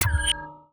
UIClick_Menu Wooble Metalic Resonate 02.wav